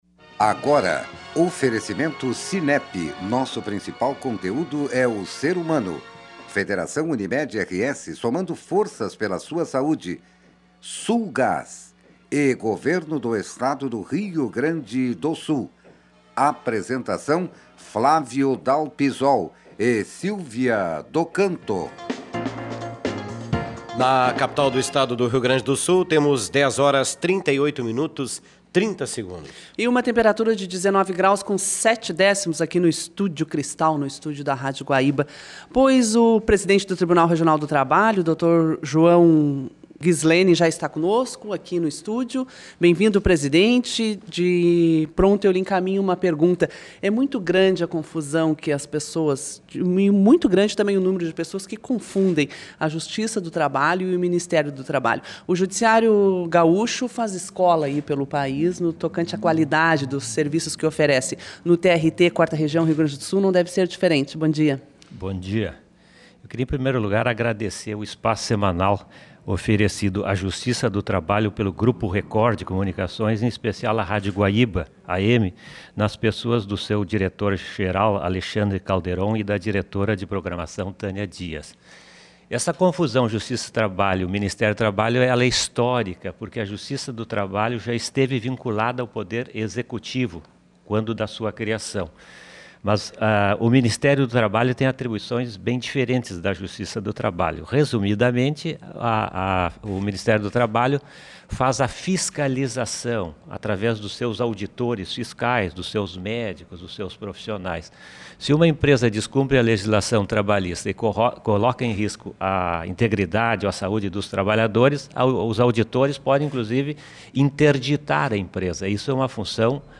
Primeira entrevista da série na Guaíba AM